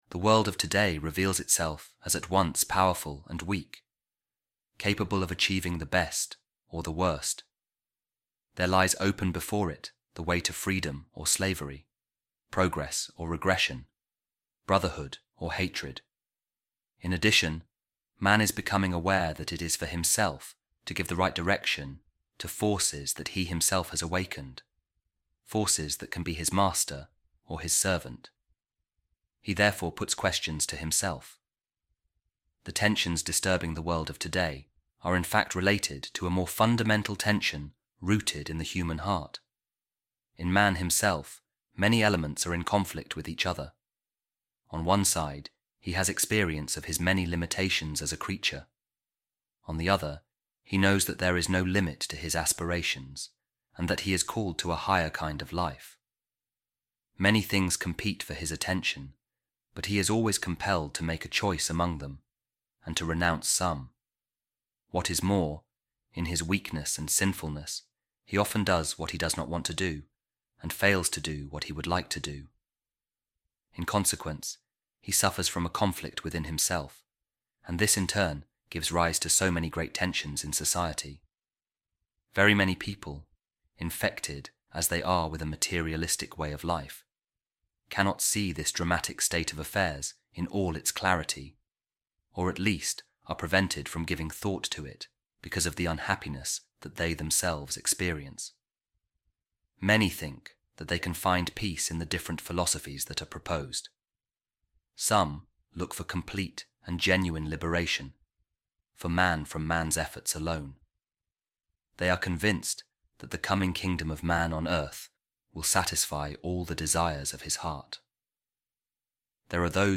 The reading from Gaudium et Spes, meaning ‘love and hope’, a key document of the Second Vatican Council, examines contradictions within modern society and the human condition.